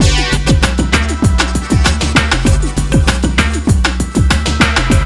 RAGGALOOP3-R.wav